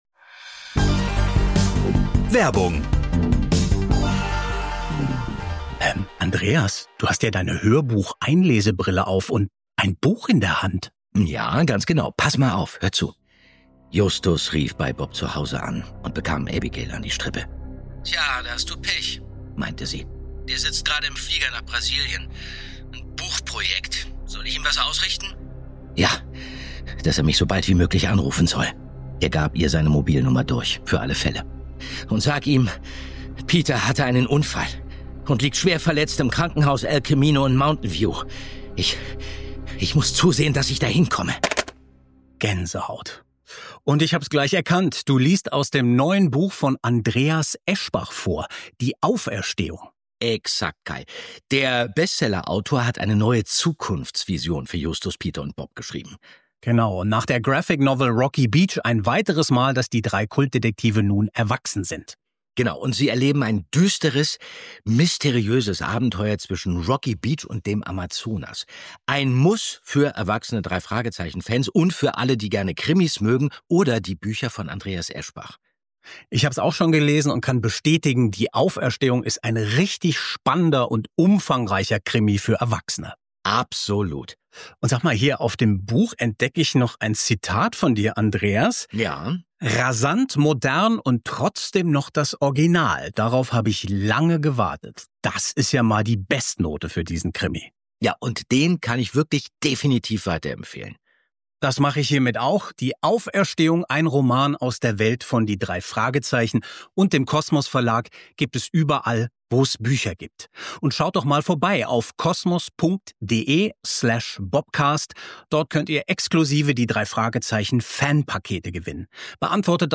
Die beiden Hörspielbesprecher danken H. G. Francis für über 600 Audioumwandlungen von Jugend- und Kinderromanen oder eigenen Manuskripten.